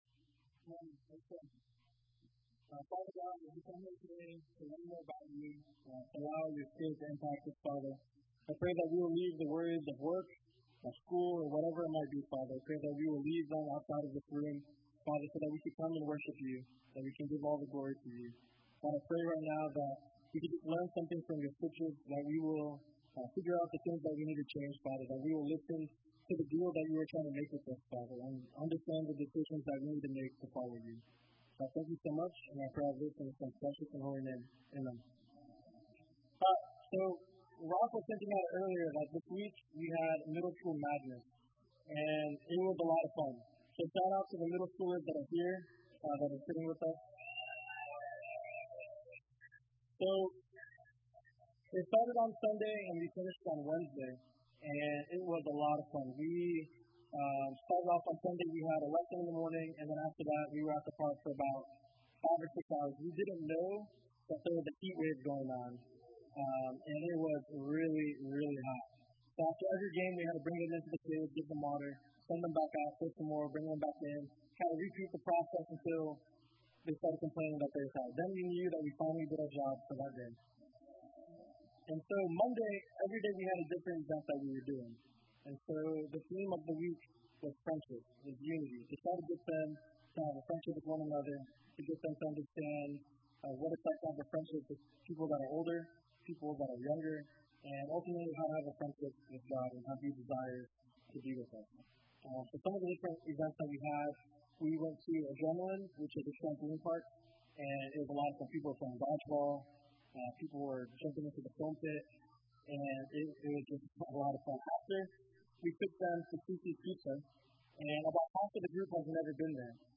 A message from the series "Gospel of Luke Series."